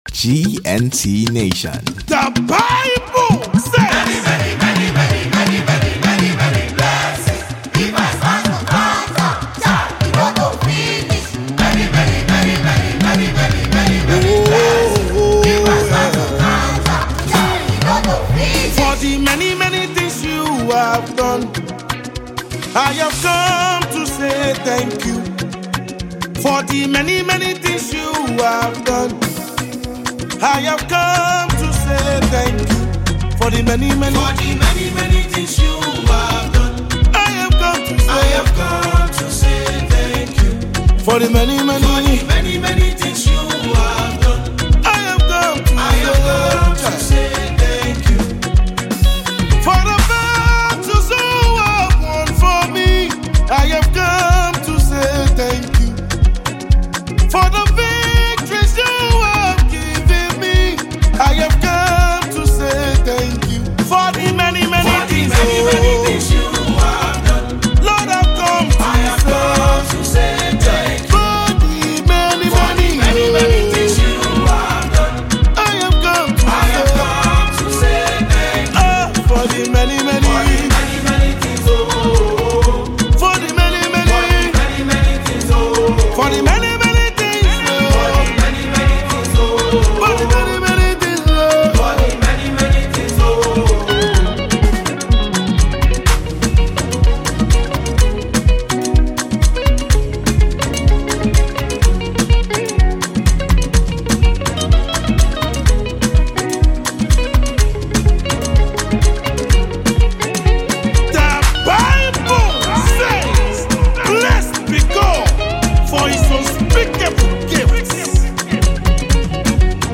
Download Latest Gospel Songs